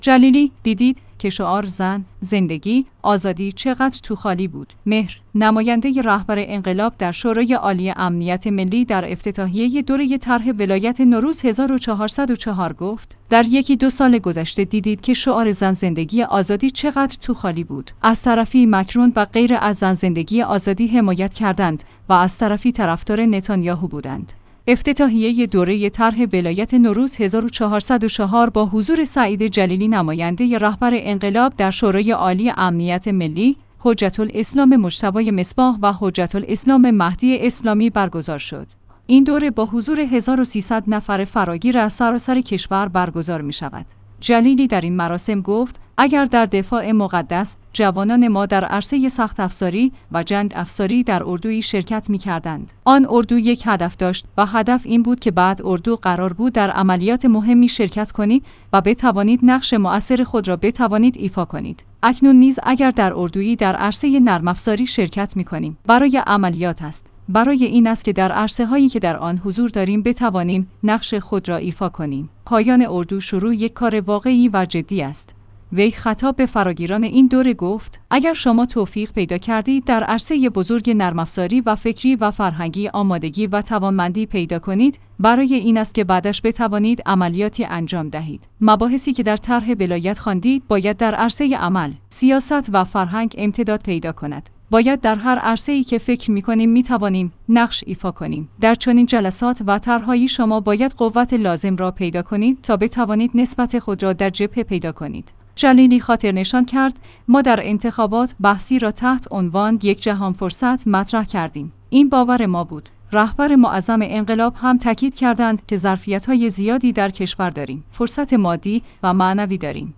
مهر/ نماینده رهبر انقلاب در شورای عالی امنیت ملی در افتتاحیه دوره طرح ولایت نوروز ۱۴۰۴ گفت: در یکی دو سال گذشته دیدید که شعار زن زندگی آزادی چقدر توخالی بود.